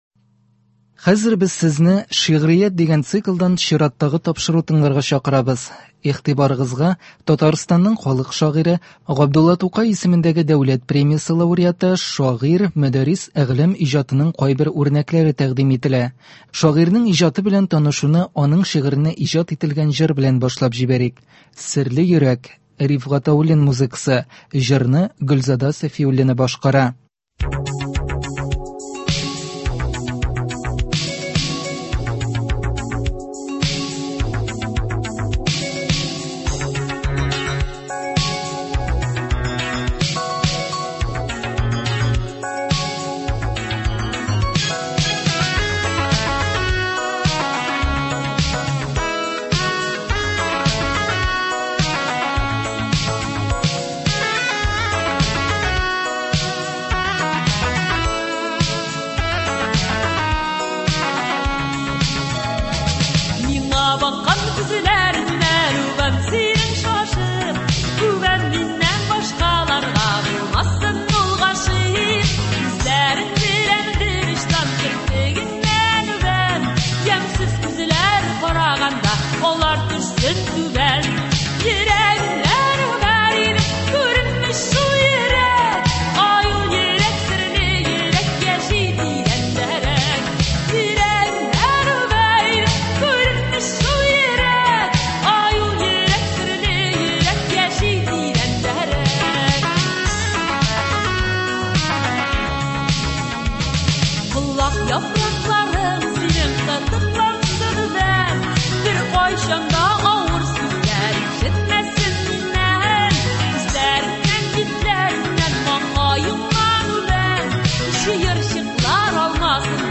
Шагыйрьнең үз тавышын ишетерсез, шигырьләрен нәфис сүз осталары укуында ишетерсез.